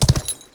horses
charge2.wav